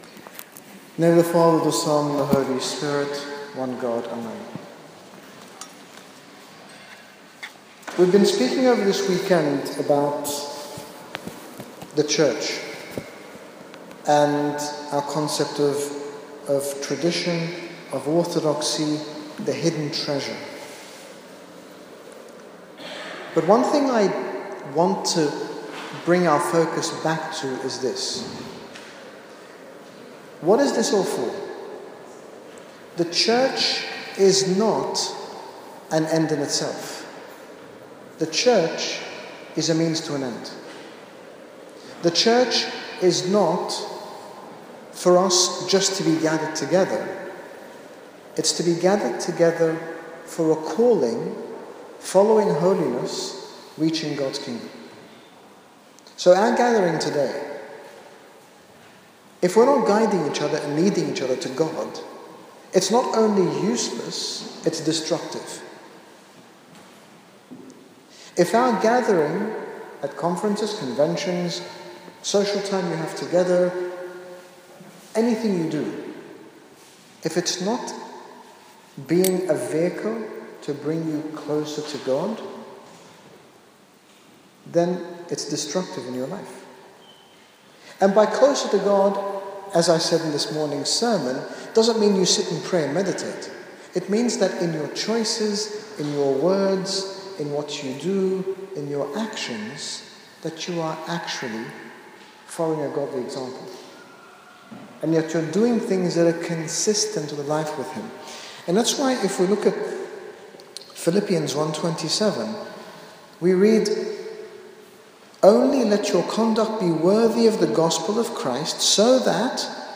In this final talk at 'Revive 2014', His Grace Bishop Angaelos, General Bishop of the Coptic Orthodox Church in the United Kingdom, speaks about our Church as a hidden treasure, a gift with immense beauty and spiritual tools to offer anyone who is seeking an intimate relationship with our Lord Jesus Christ.
The Beauty of Orthodoxy - Last talk in Revive 2013.mp3